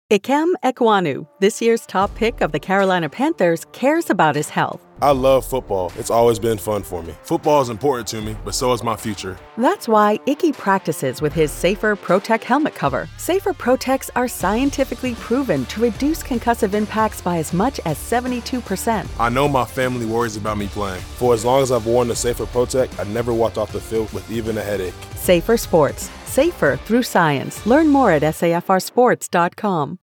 Listen for the spots on the pre-game show and during the live broadcast. He talks about balancing his love of football and how the SAFR Helmet Cover helped to minimize the risk of injury, and the concern of his family.